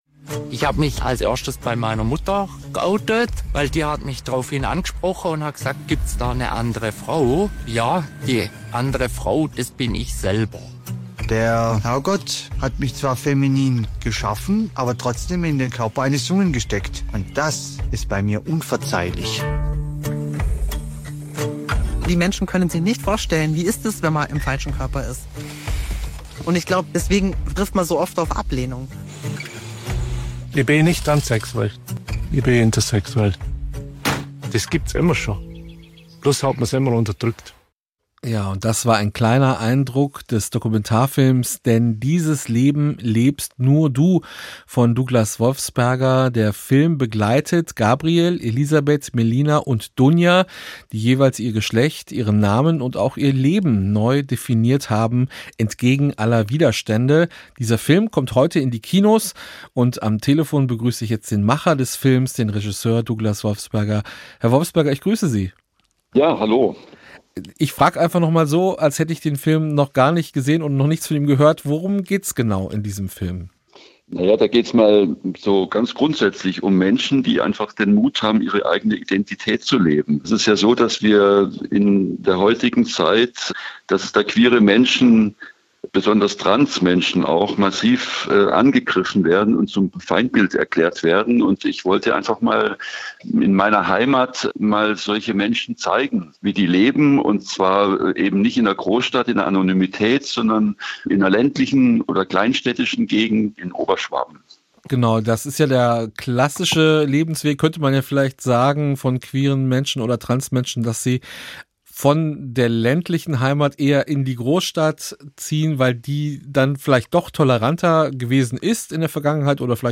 Gespräch
Interview mit